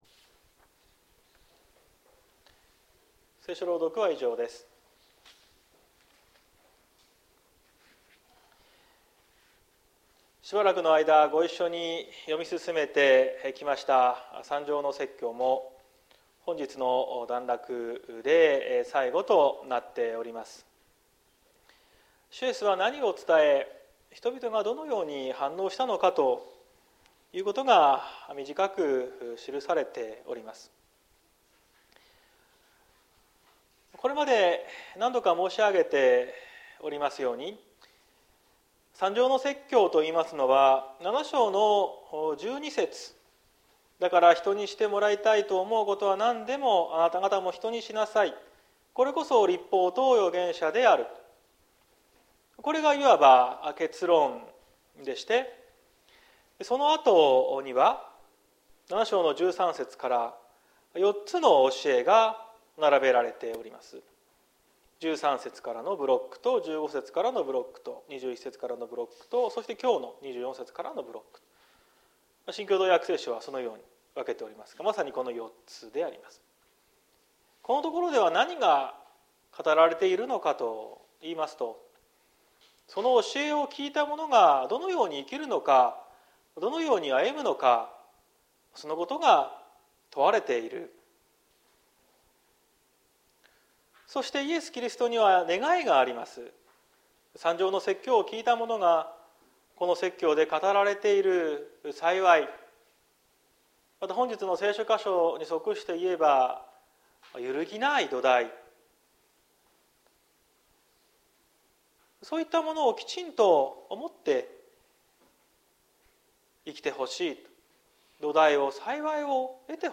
2023年05月14日朝の礼拝「土台のある人生」綱島教会
綱島教会。説教アーカイブ。